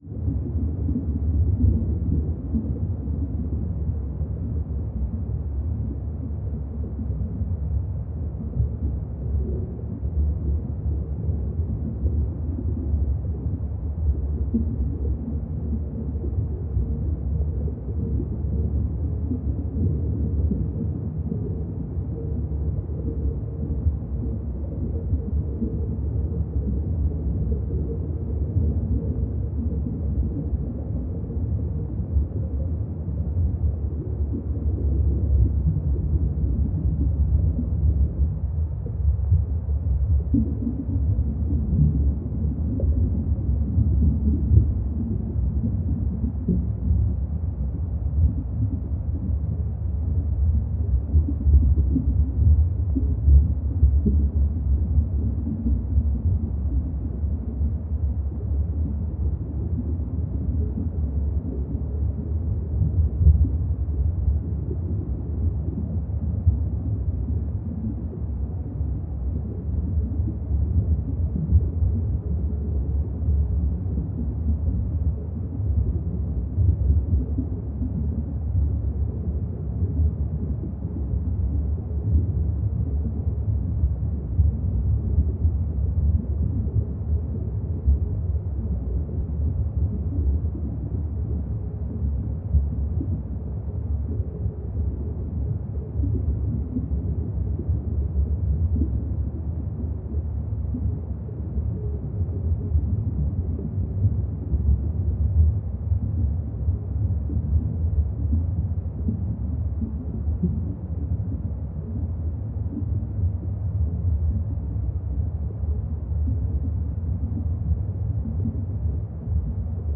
Dive Deep - Water Flow 01.wav